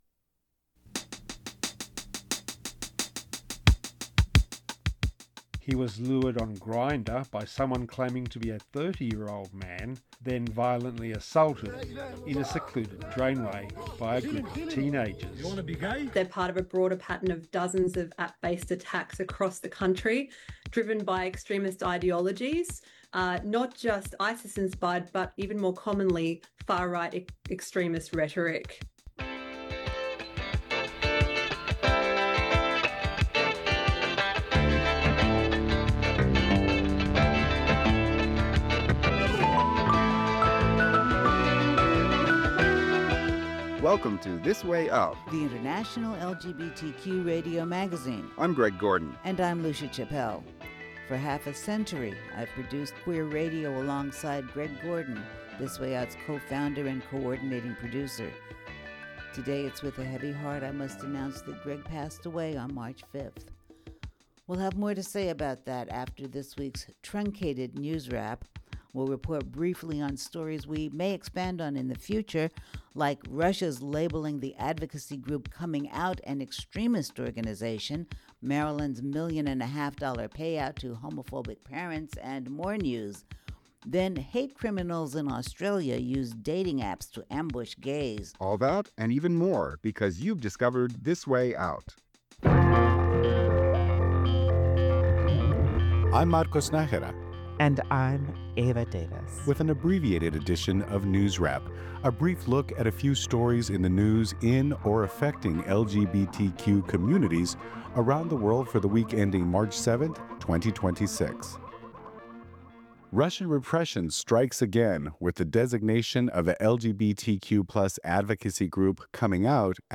The International LGBTQ Radio Magazine Program